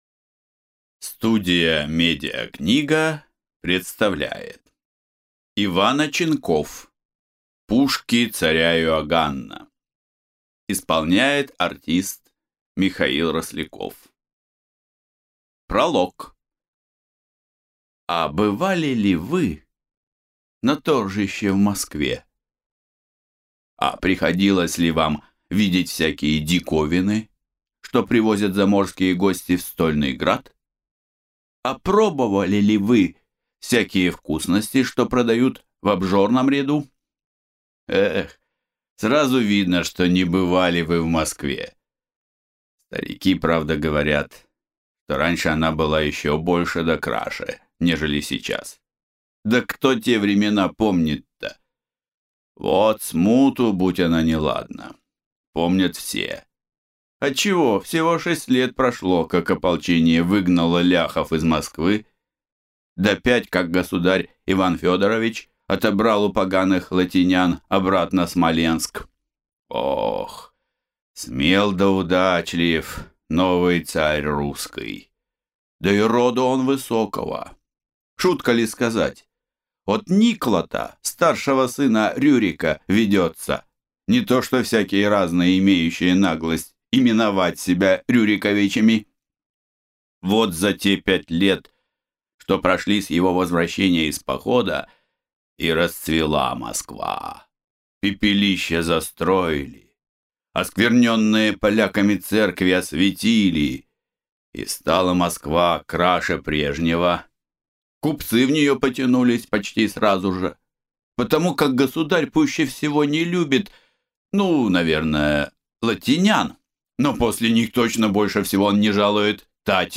Аудиокнига Пушки царя Иоганна | Библиотека аудиокниг